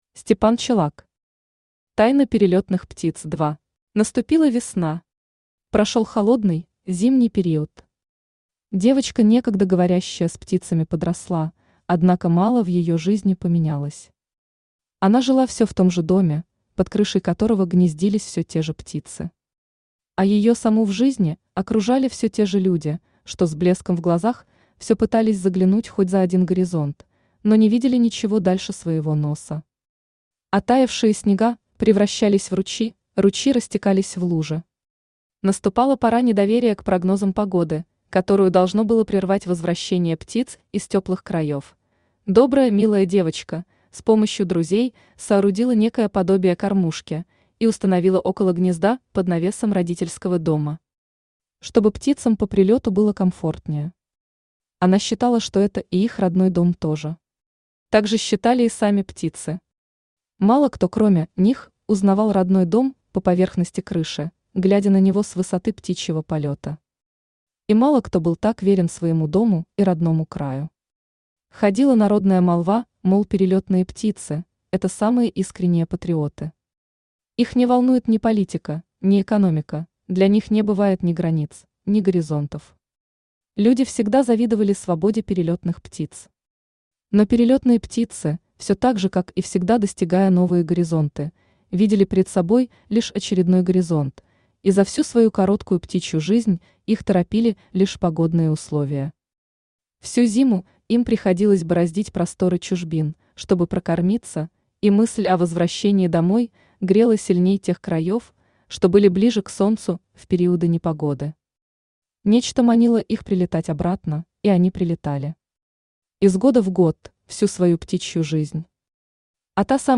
Аудиокнига Тайна перелётных птиц 2 | Библиотека аудиокниг
Aудиокнига Тайна перелётных птиц 2 Автор Степан Дмитриевич Чолак Читает аудиокнигу Авточтец ЛитРес.